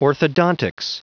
Prononciation du mot orthodontics en anglais (fichier audio)